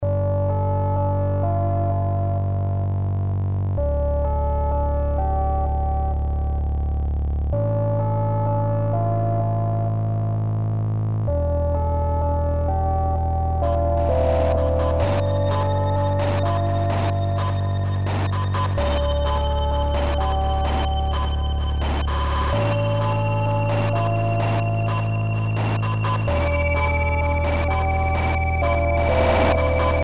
On their site, they posted the 1.5 seconds of Funkadelic guitar at the centre of the case and invited allcomers to compose 30 second song using only this sample.
dark electronica to video game and synthesized insect sounds.